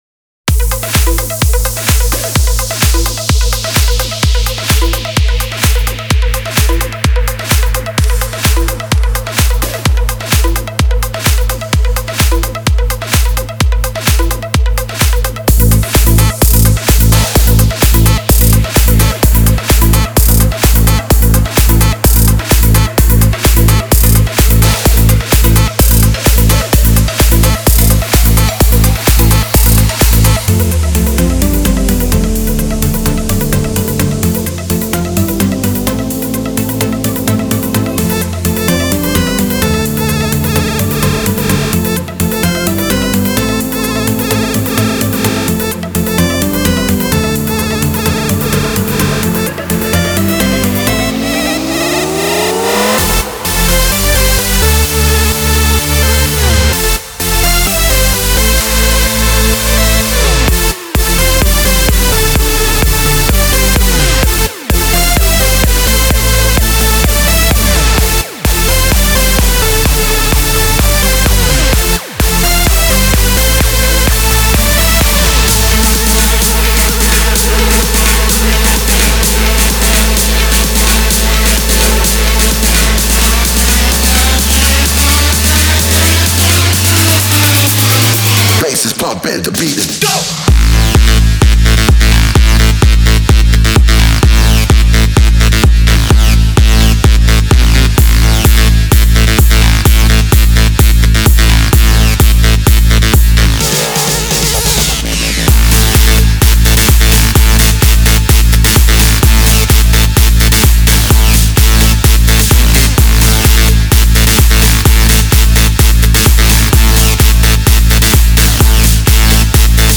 Категория: Электро музыка » Электро-хаус